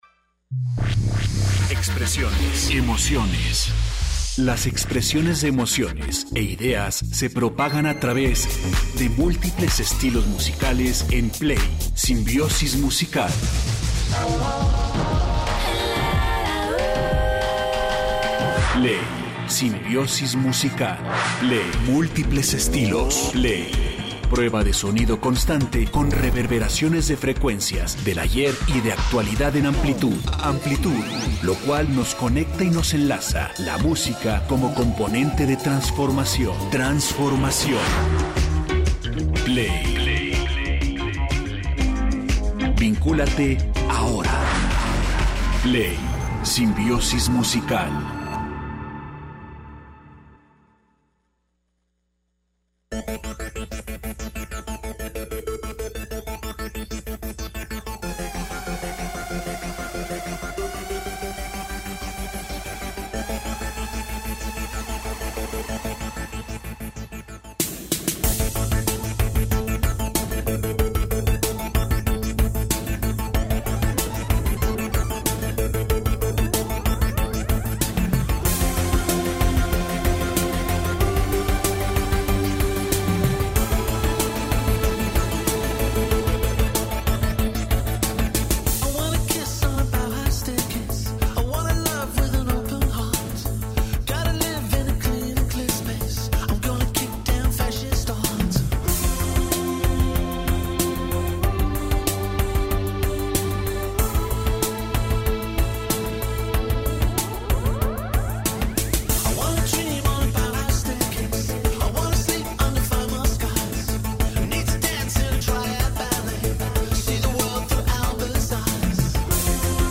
Qué mejor que iniciar la semana con buena música.